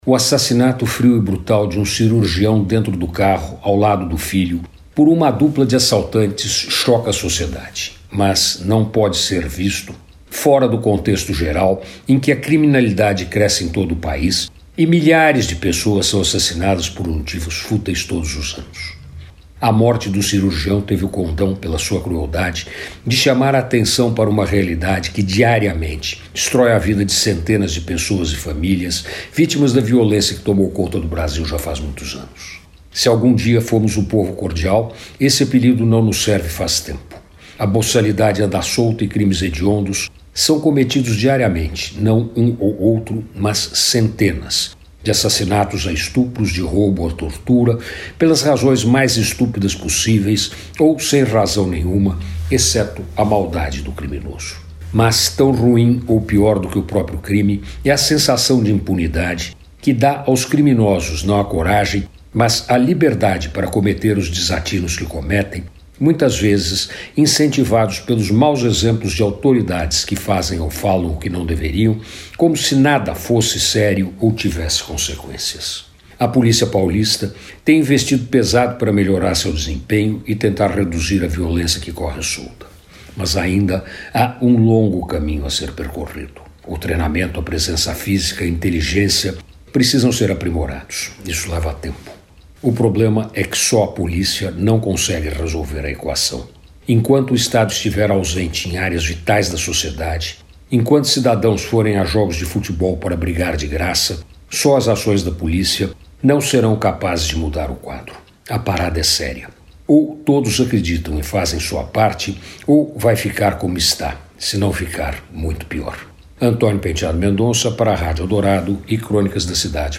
Crônicas da Cidade vai ao ar de segunda a sexta na Rádio Eldorado às 5h55, 9h30 e 20h.